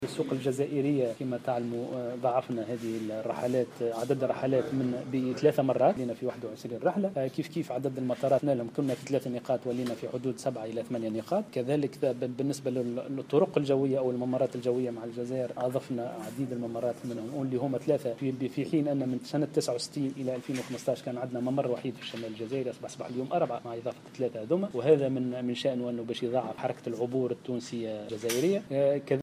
كما أضاف أيضا خلال ندوة صحفية انعقدت اليوم لتسليط الضوء على الاتفاقيات الدولية في مجال الطيران المدني مع عدد من شركات الطيران الأوروبية والعربية والإفريقية أنه تم الترفيع في عدد المطارت التي ستستغلها الناقلات الجوية التونسية في الجزائر من 3 مطارات إلى 8 مطارات،فضلا عن إحداث 3 ممرات جوية مع الجزائر.